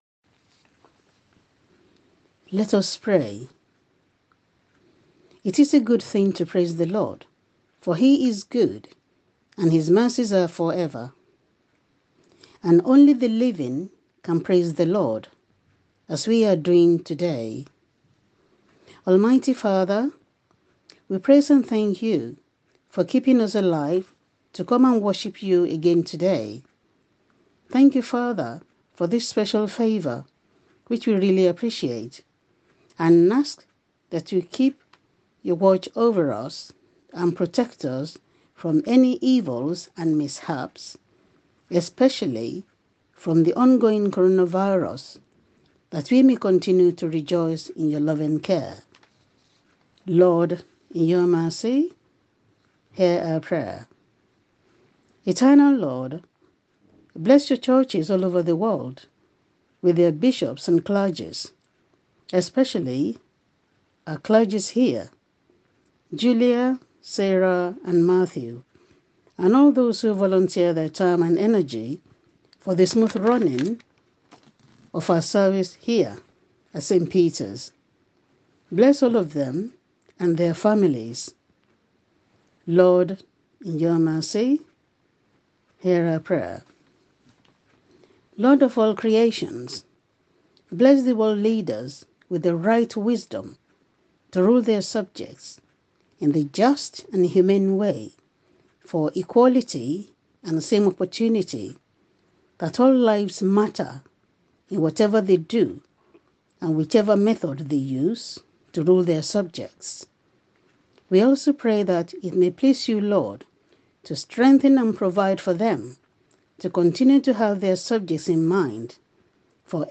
Intercessions
Intercessions-july-5.m4a